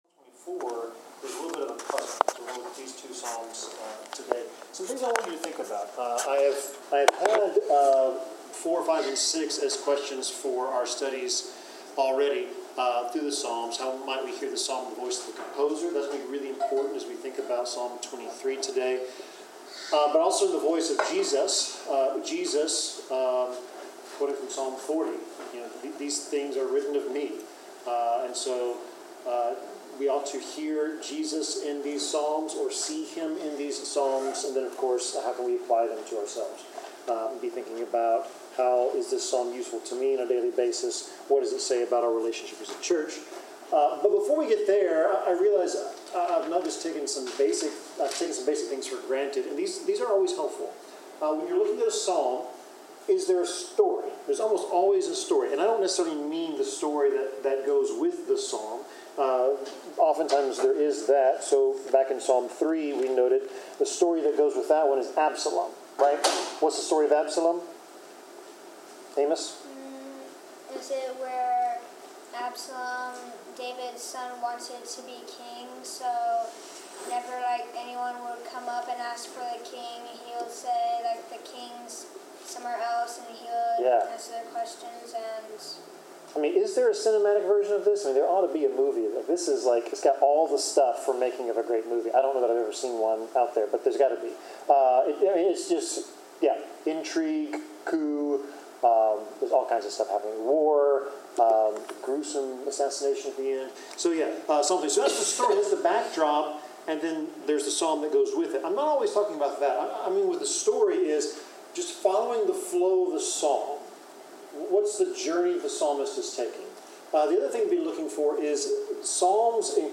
Bible class: Psalms 23-24
Service Type: Bible Class